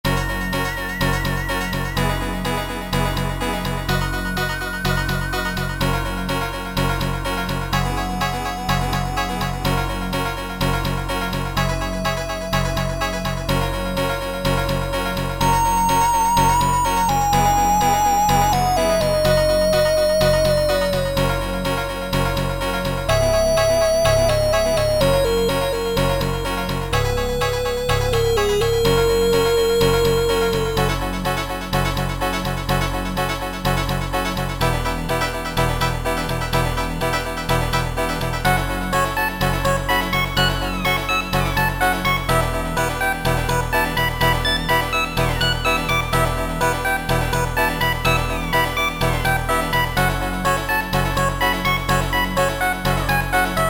Sound Format: Soundmon 2